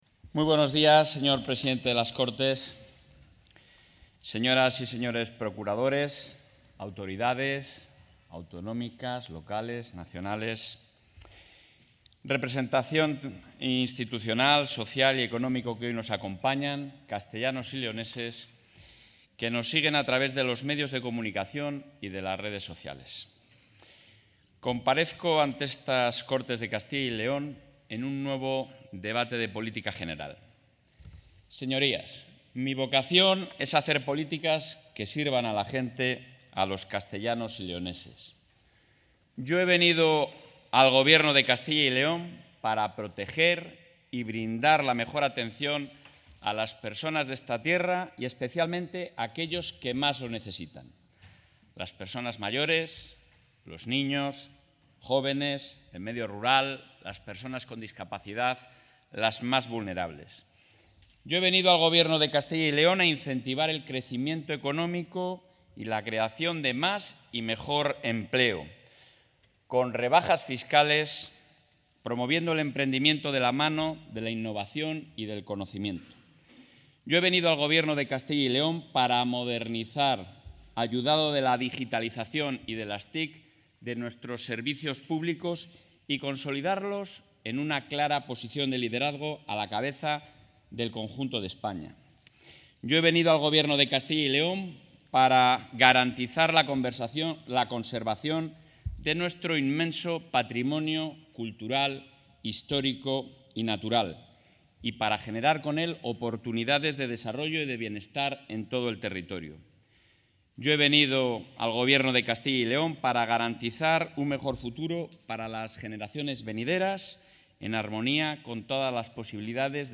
Intervención del presidente de la Junta.
Durante su segundo Debate sobre Política General, que se ha iniciado hoy en las Cortes de Castilla y León, el presidente del Ejecutivo autonómico, Alfonso Fernández Mañueco, ha centrado sus palabras en torno a una cuestión principal: la de hacer políticas que sirvan a la gente, a los ciudadanos de Castilla y León, para, de este modo, brindar la mejor atención a todas las personas de esta tierra, especialmente a aquellos que más lo necesitan.